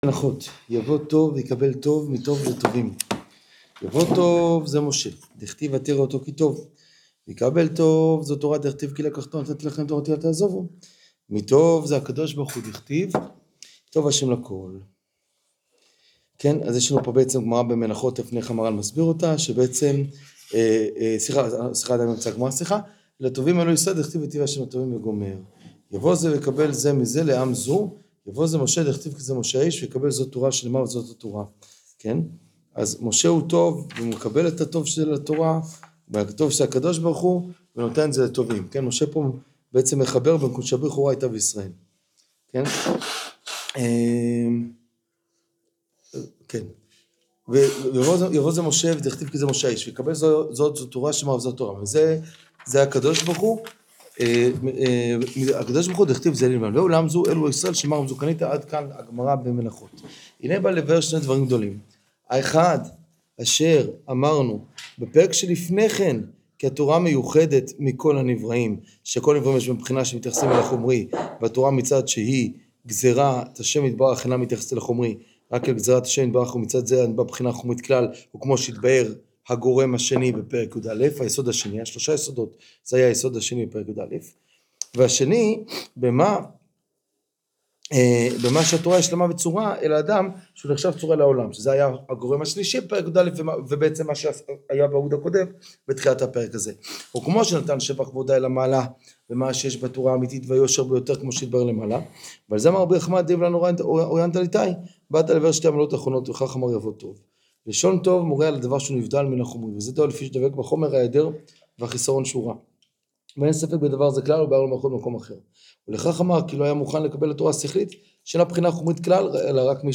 שיעור תפארת ישראל סוף פרק יב